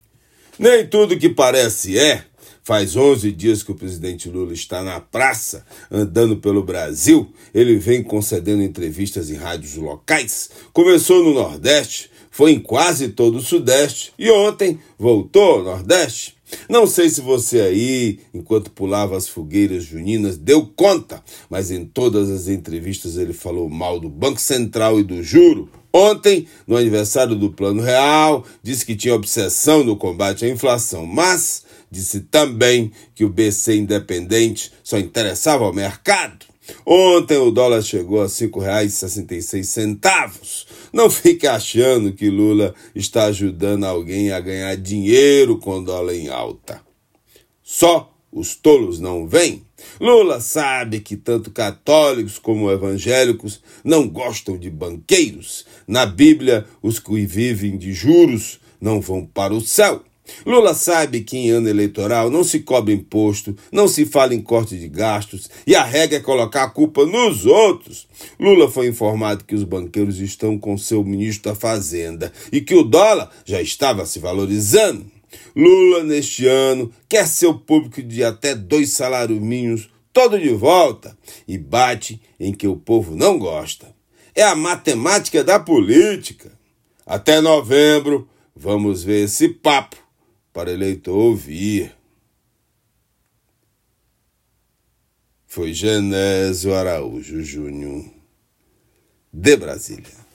Comentário desta terça-feira
direto de Brasília.